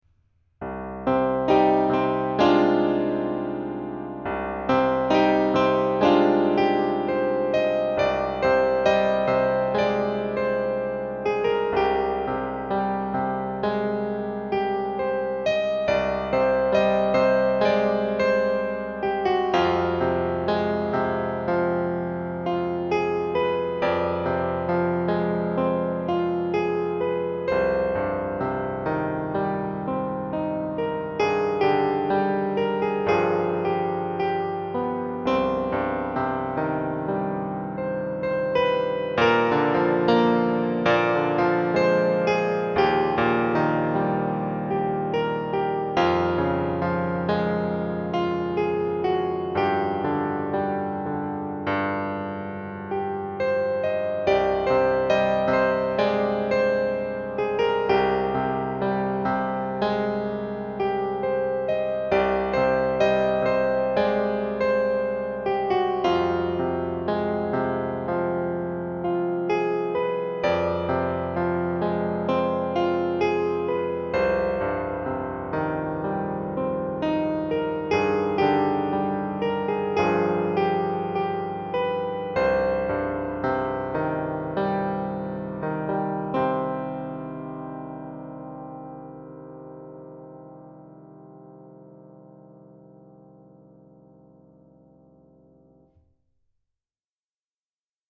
Elektronické klávesy